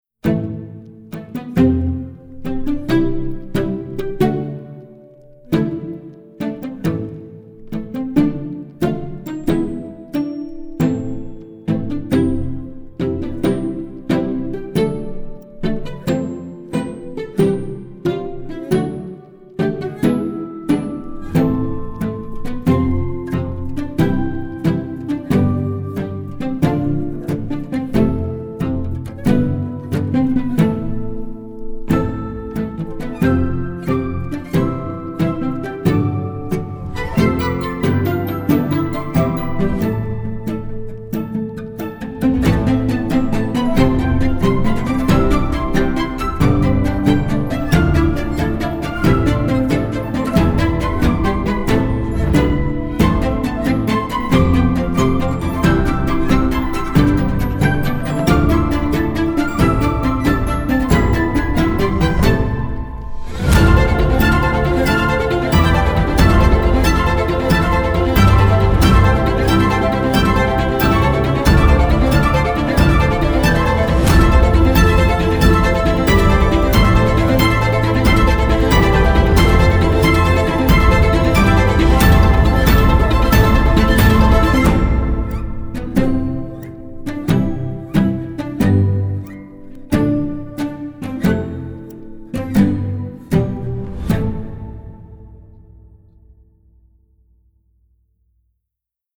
String-focused TV and Ad promo scores